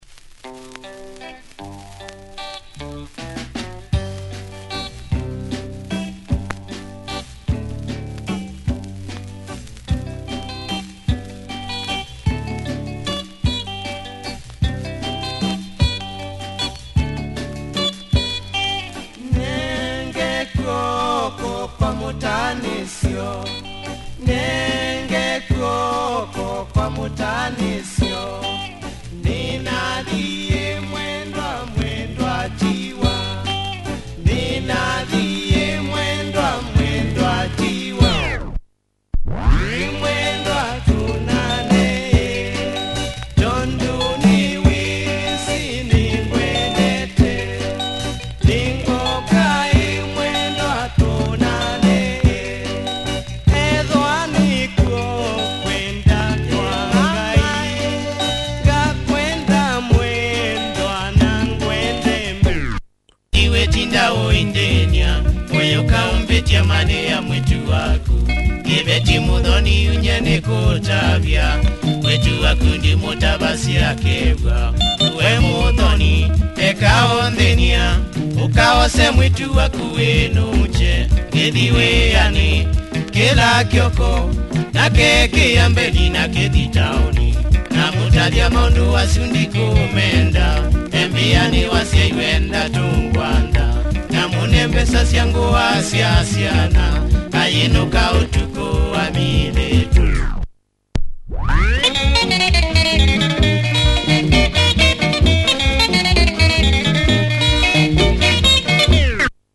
Traditional kamba sounds, check audio!